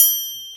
percussion 15.wav